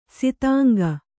♪ sitānga